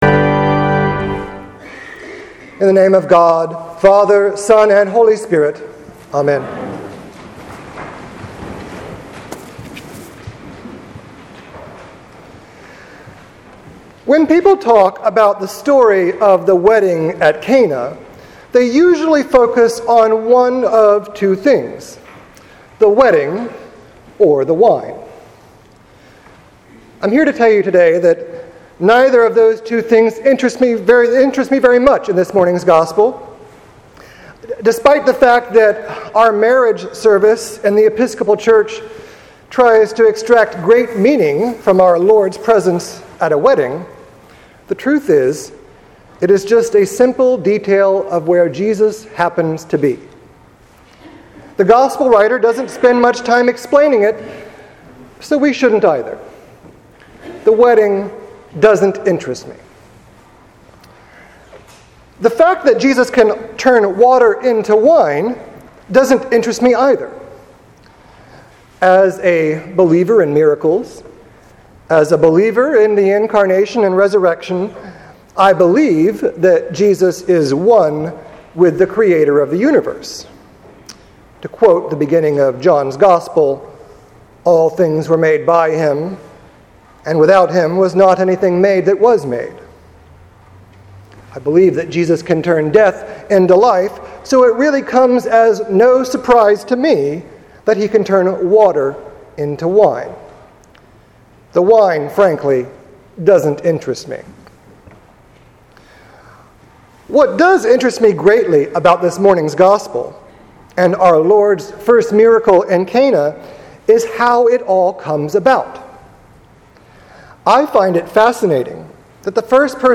Sermon for the Second Sunday of Epiphany 2016.